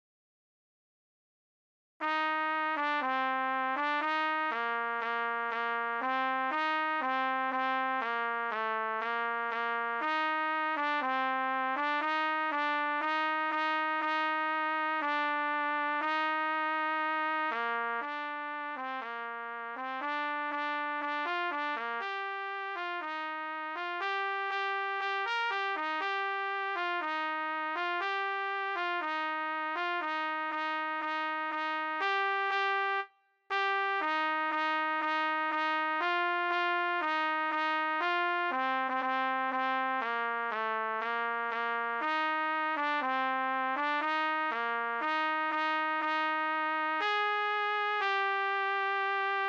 B=Harmony-for intermediate players